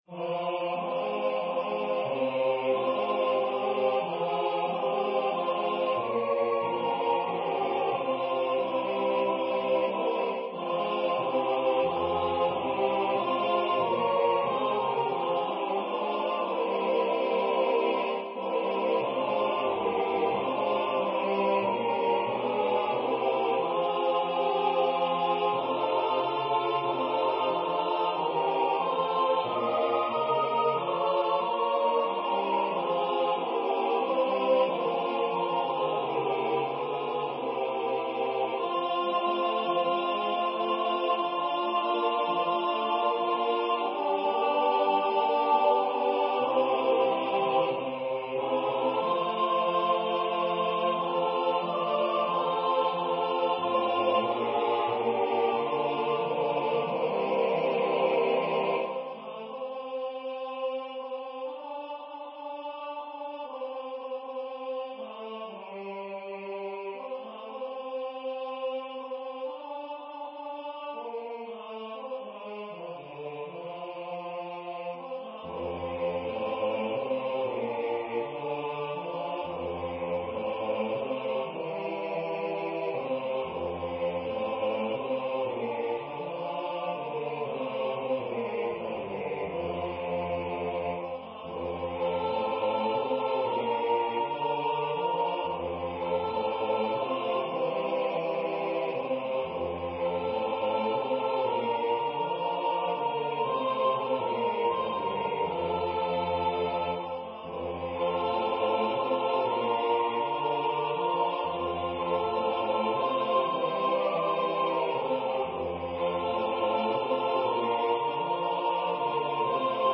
The Agnus Dei movement starts out as a reflective waltz in F minor that the bass interrupts with a happy thought.
The opening theme from the Introit is heard again, then the tenor(s) lead the choir in joyful contrapuntal expression of the eternal light to come. Perhaps some clapping (on the offbeats, please!) in the last part?